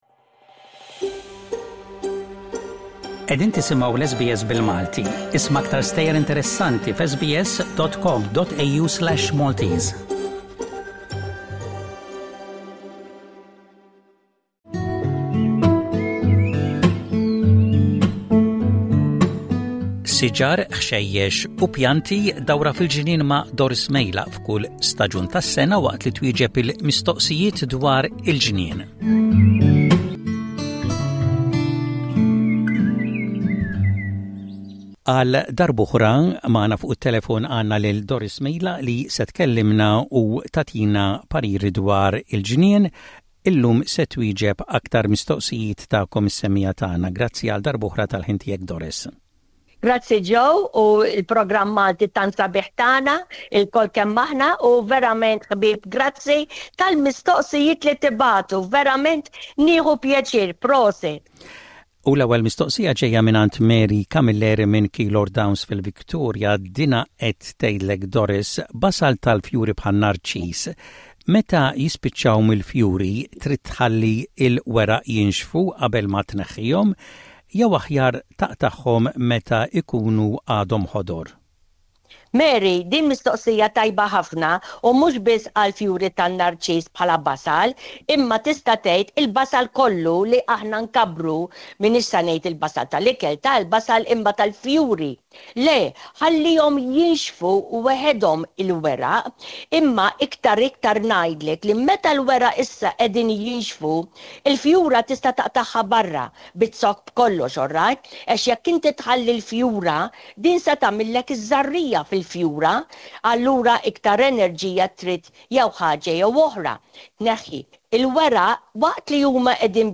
Fil-ġnien | mistoqsijiet u tweġibiet